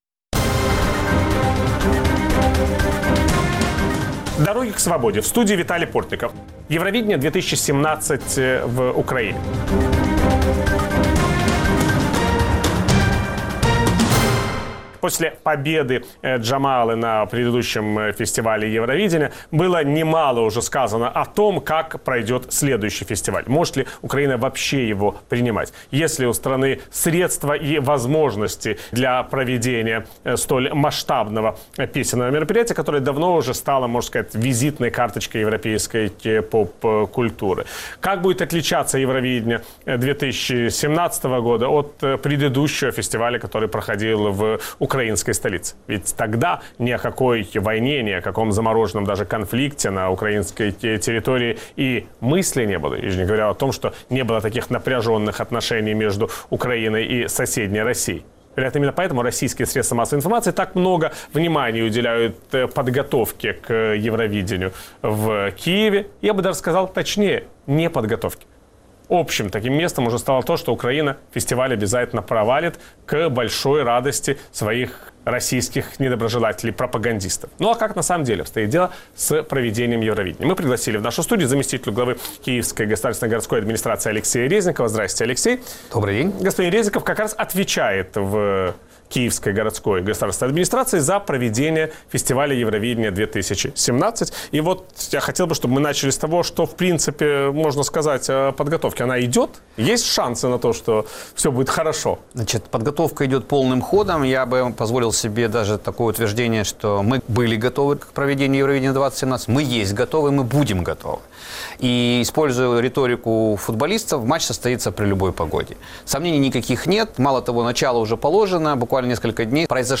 Виталий Портников беседует с заместителем главы Киевской городской государственной администрации Алексеем Резниковым.